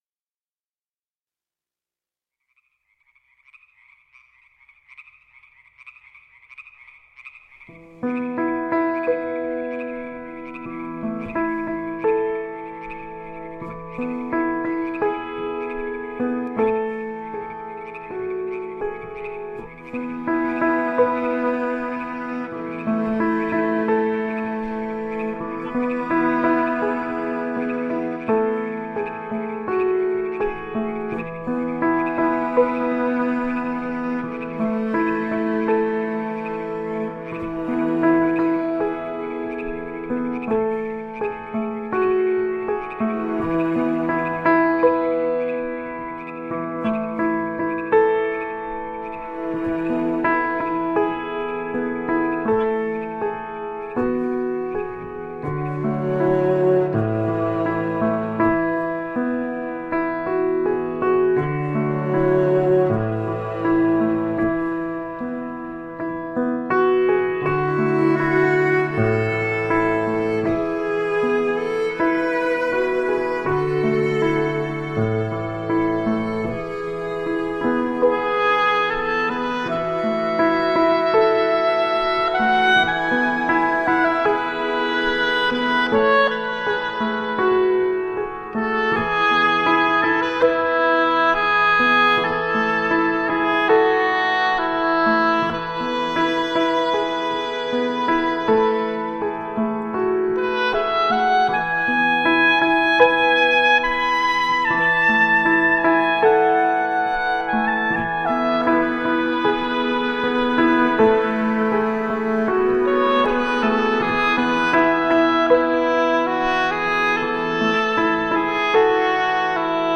Intriguing piano compositions with dynamic accompaniment.
Tagged as: New Age, Folk, Instrumental